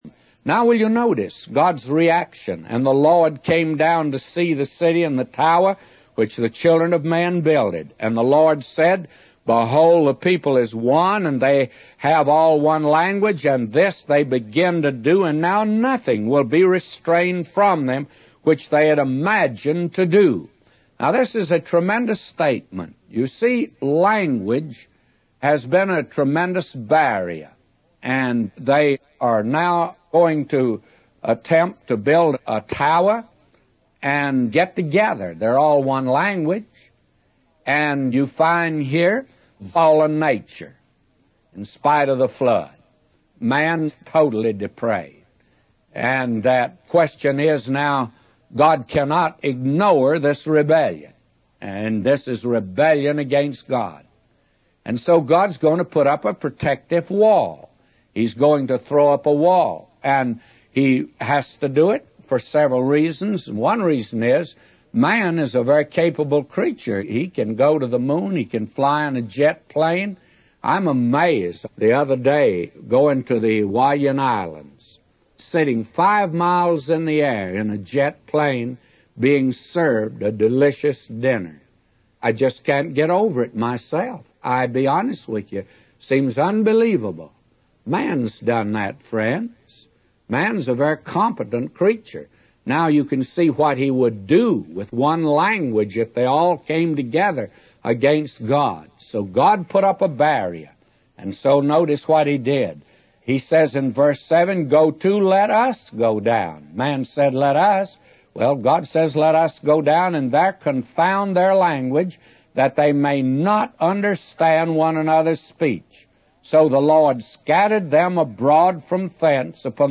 In this sermon, the preacher discusses the story of the Tower of Babel from the Bible.